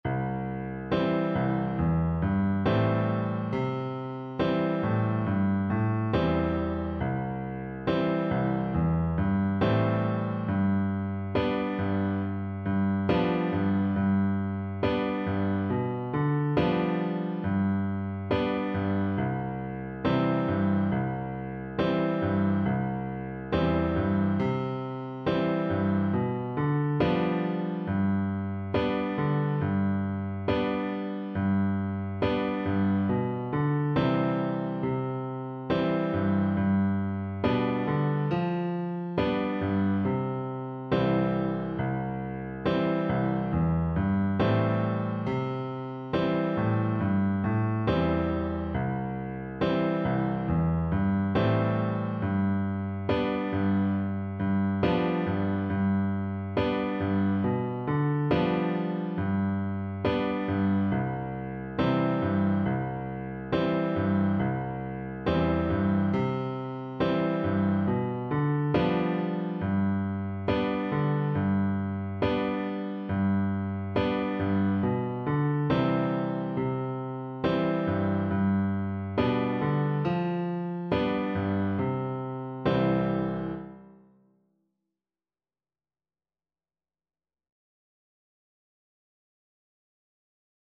Flute
Traditional Music of unknown author.
C major (Sounding Pitch) (View more C major Music for Flute )
4/4 (View more 4/4 Music)
Moderato